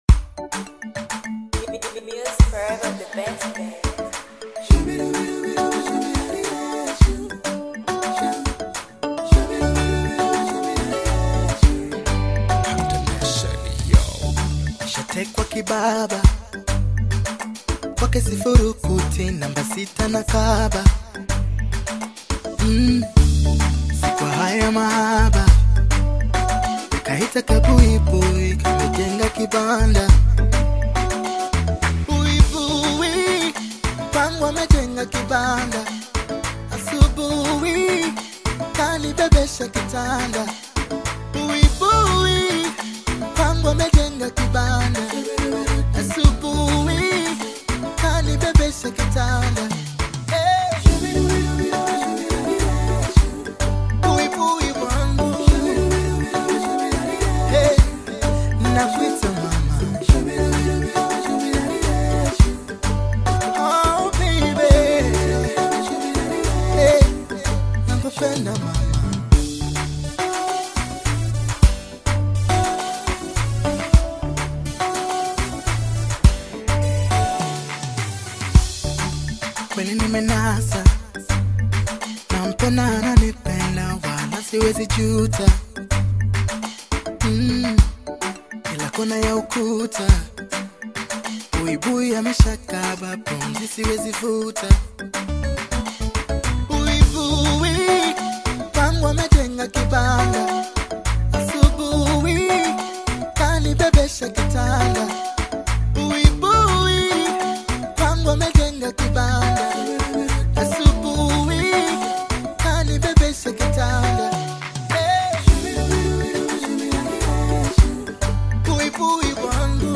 R&B/Soul single